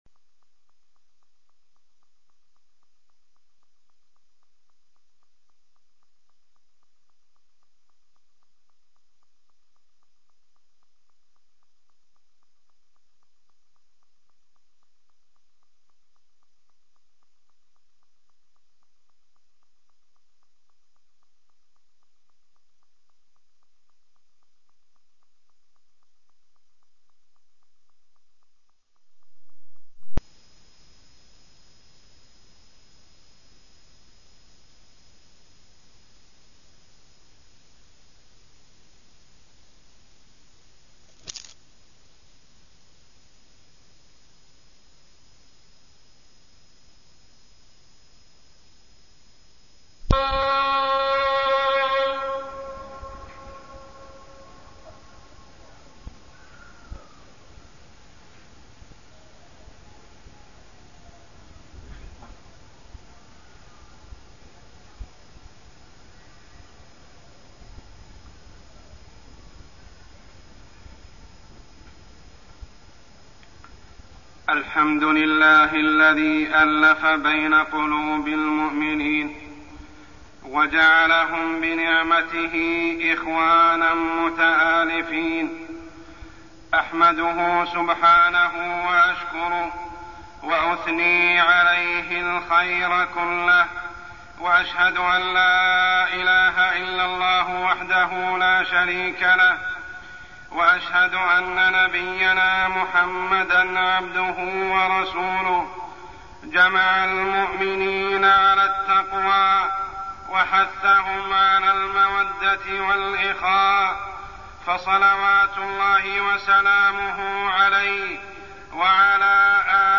تاريخ النشر ١٩ شوال ١٤١٦ هـ المكان: المسجد الحرام الشيخ: عمر السبيل عمر السبيل رابطة الأخوة